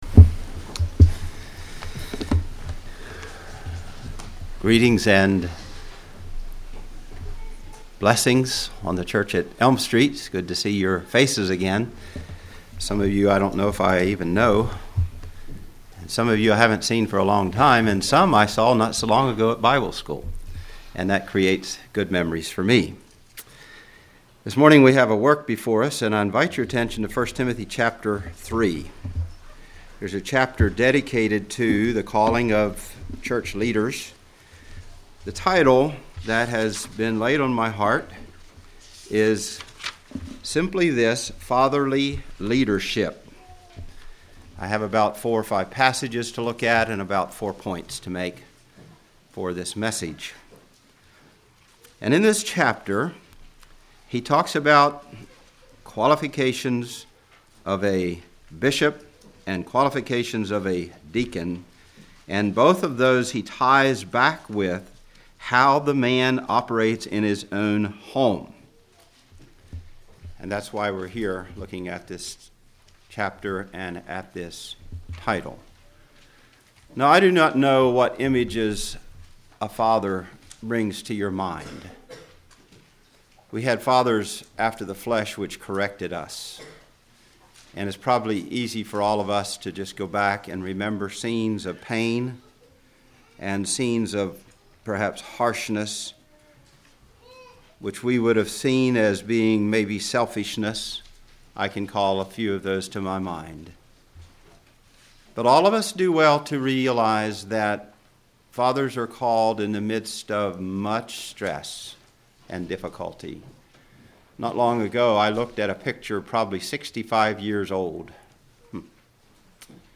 Congregation: Elm Street